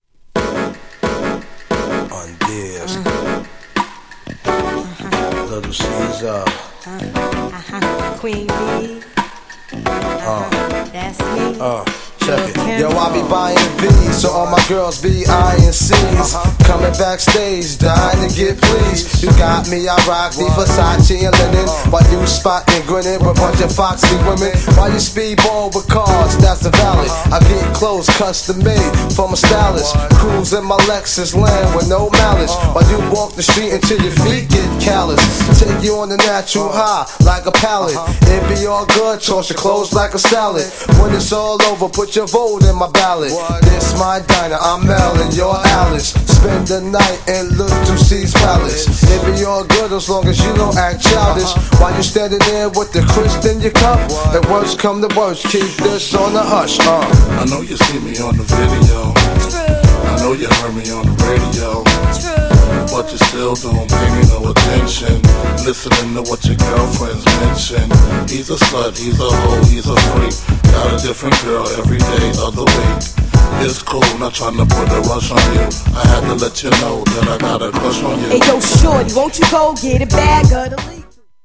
GENRE Hip Hop
BPM 96〜100BPM